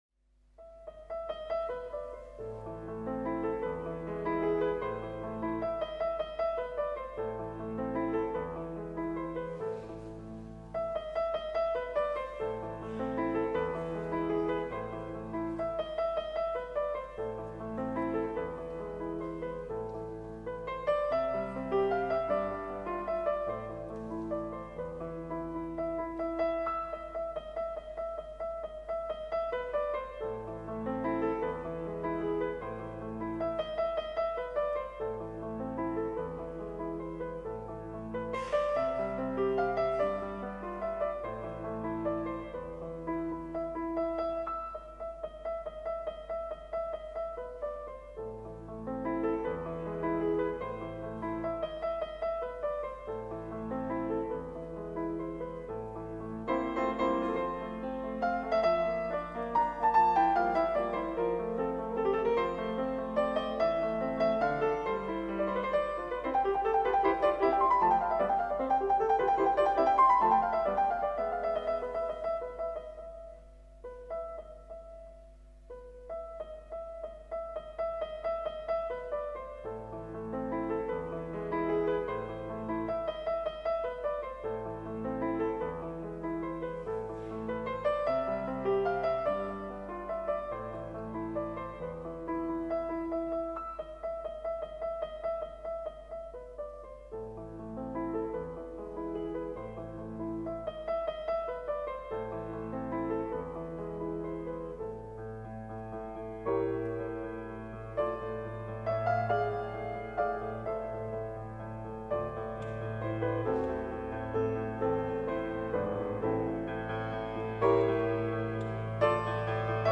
Classical Songs